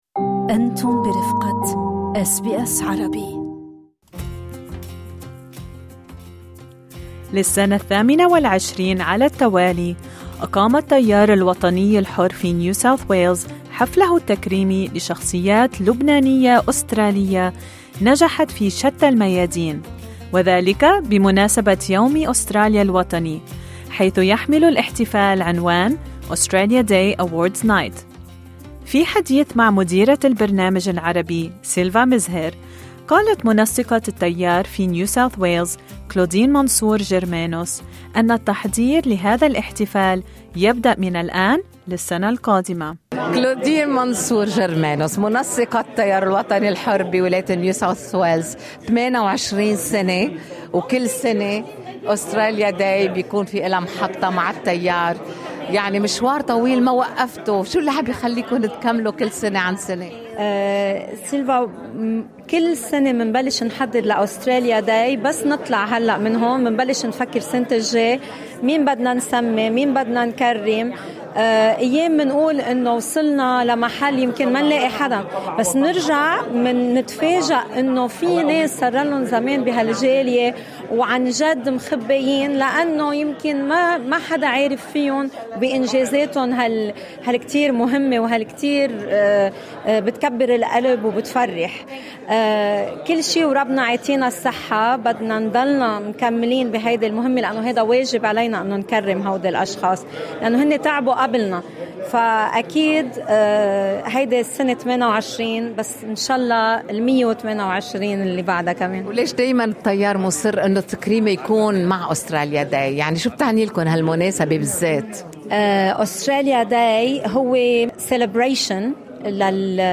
وحضرت شخصيات سياسية واجتماعية واعلامية الاحتفال منها وزير خدمات الطوارئ في حكومة نيو ساوث ويلز الوزير جهاد ديب الذي تحدث عن شعوره بالمناسبة الى ميكروفون البرنامج العربي.